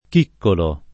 chiccolo [ k & kkolo ]